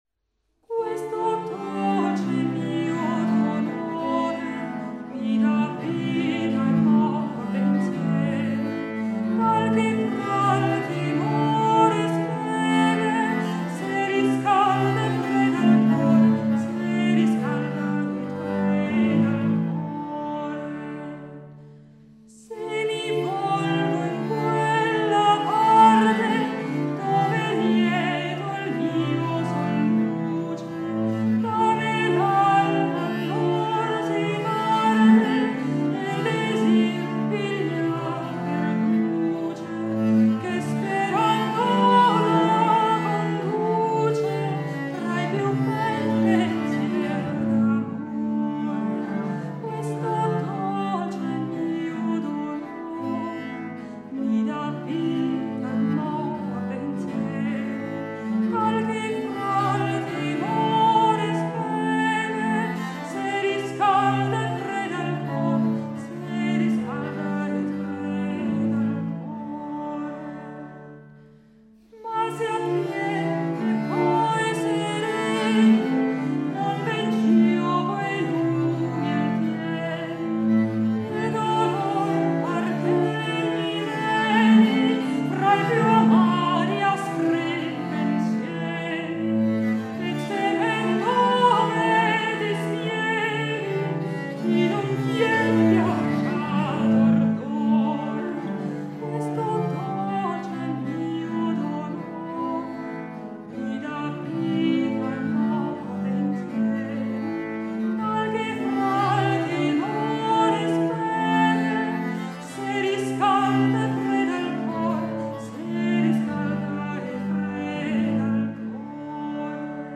Registrazione "Questo dolce mio dolore" - Barzelletta — Laurea Magistrale in Culture e Tradizioni del Medioevo e del Rinascimento